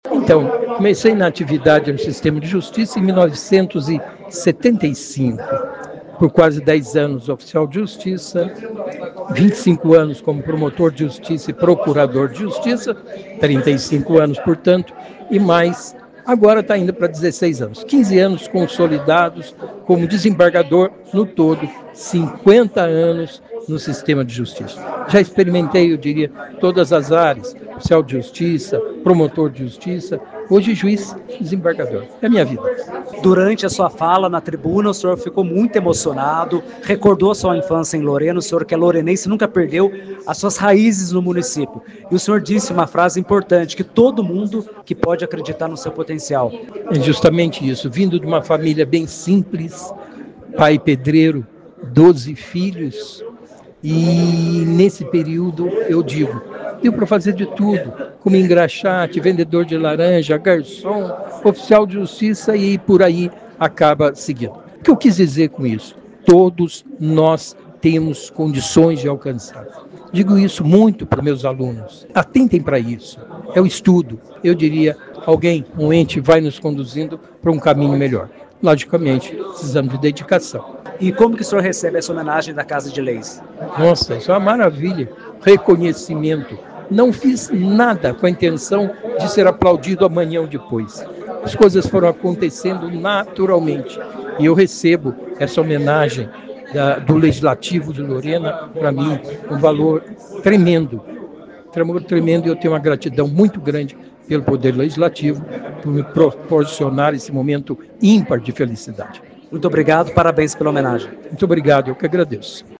Homenagem foi entregue, na sessão desta segunda-feira (5), no plenário do Legislativo
Áudio do desembargador do Tribunal de Justiça do estado de São Paulo, Dr. Luiz Antonio Cardoso